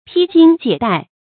披襟解带 pī jīn jiě dài
披襟解带发音